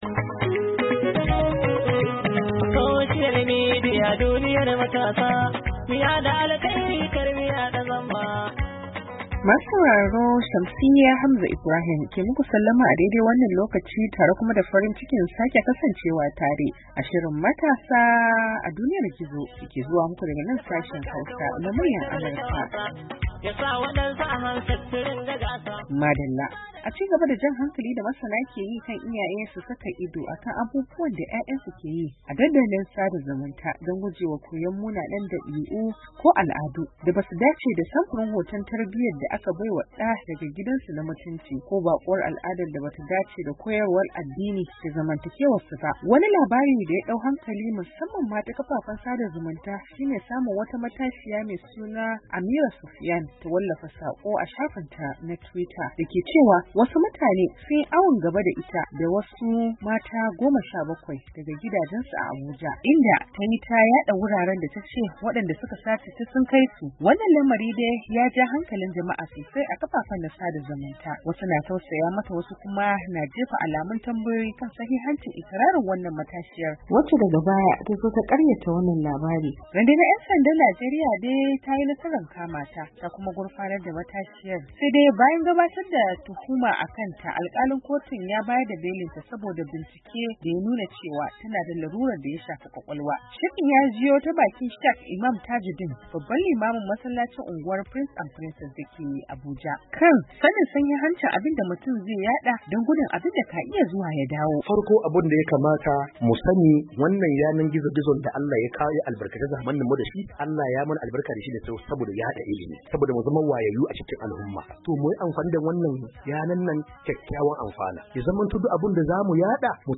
MATASA A DUNIYAR GIZO: Tattaunawa Kan Yadda Iyaye Za Su Rika Sa Ido Akan Abubuwan Da 'Ya'yansu Ke Yi A Kafafen Sada Zumunta, Yuli 03, 2022